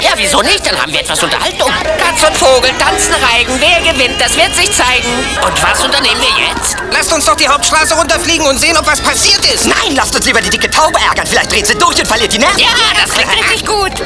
Ein paar Samples aus dem Trickfilm Oliver & Olivia, Anfang 1992 in Berlin synchronisiert.
diverse Stadtspatzen
oli-spatz.mp3